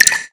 drum-hitwhistle.wav